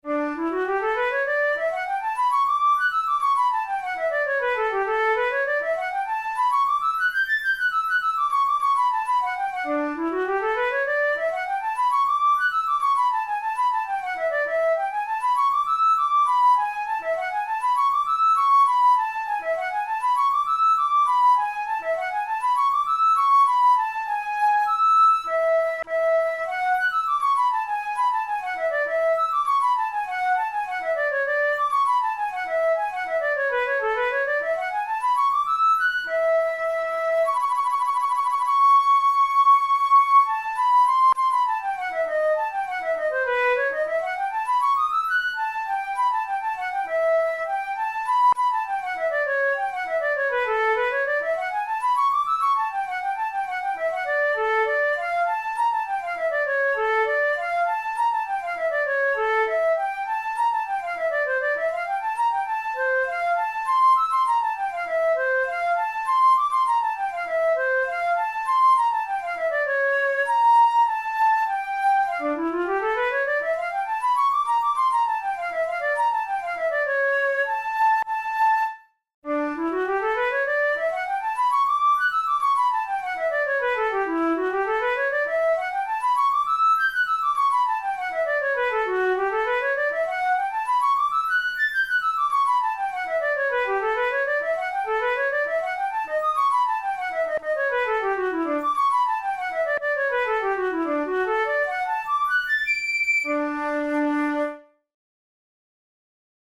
This Allegretto is the fifth piece from a collection of 20 Capricci by Italian composer Saverio Mercadante.
Categories: Classical Written for Flute Difficulty: intermediate